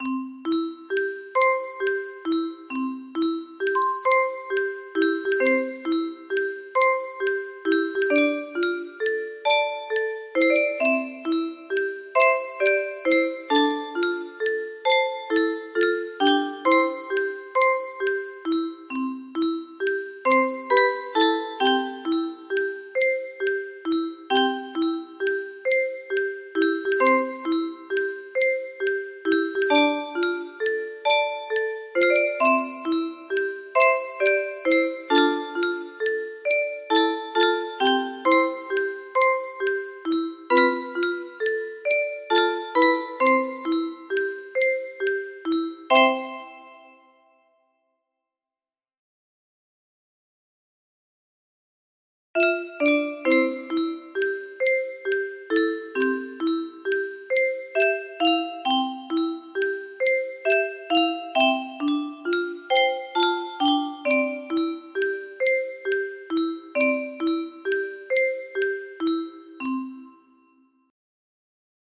pt1 edit music box melody